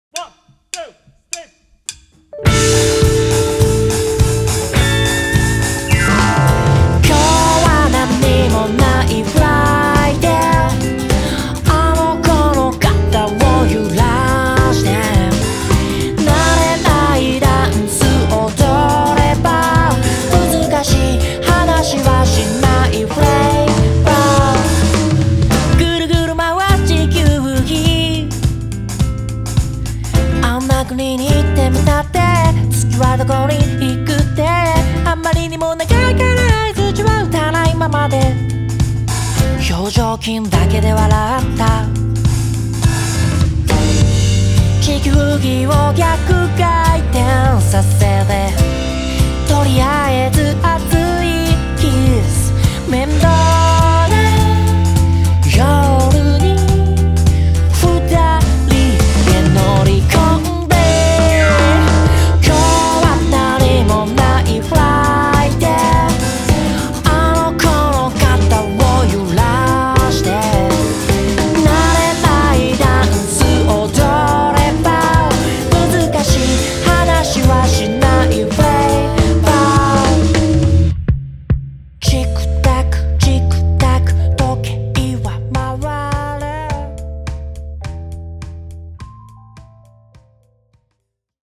今回、イン・ザ・ボックスでのミックスと8816を通したミックスを用意しましたので、違いが分かると思います。